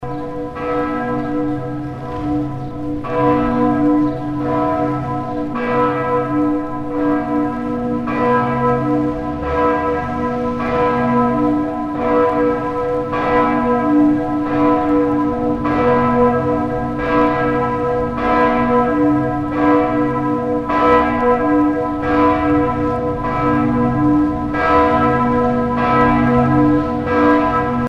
La cloche du couvre-feu
Les cloches de la cathédrale de Strasbourg